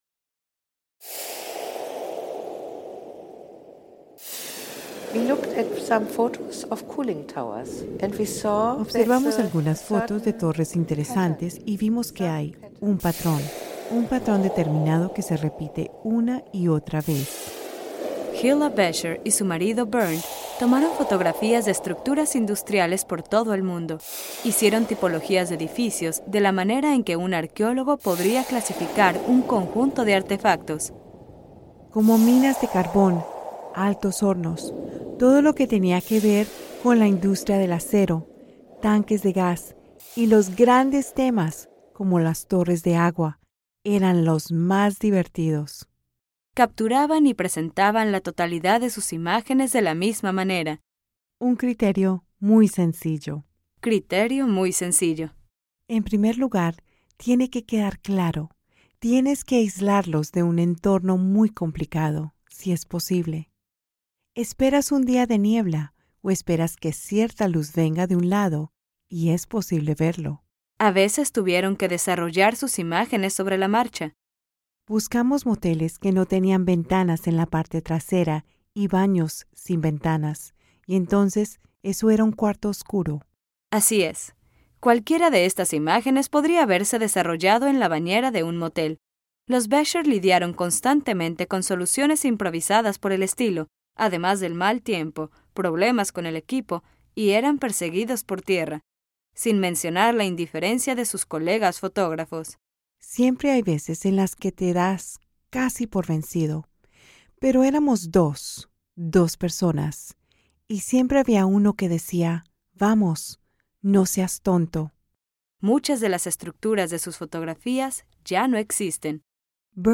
Audio Stories
SFX: A brief, simple industrial sound, like water pumping into a tower, grain pouring out of a tower, or a blast furnace blasting, repeated quietly underneath.